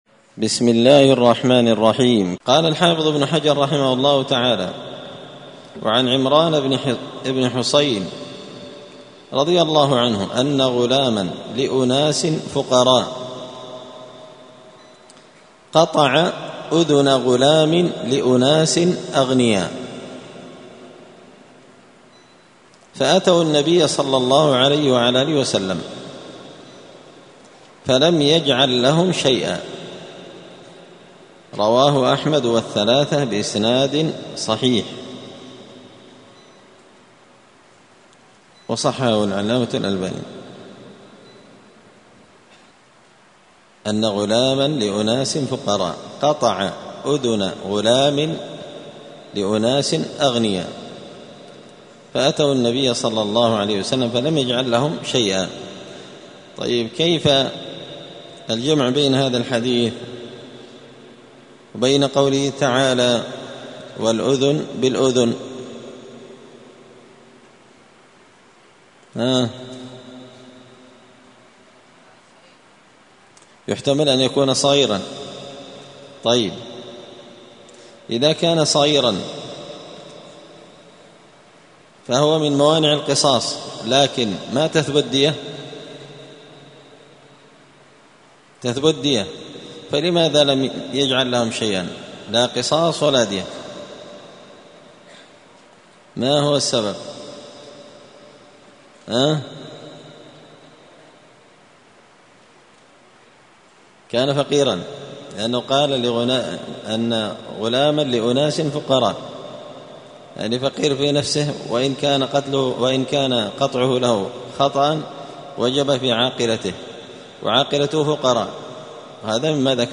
دار الحديث السلفية بمسجد الفرقان بقشن المهرة اليمن 📌الدروس اليومية